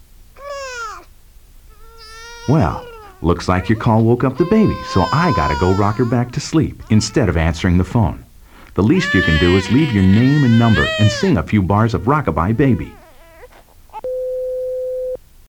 This is a sound effects compilation disguised as a tape for your answering machine.
For a sound effects CD, the quality of this one is pretty good.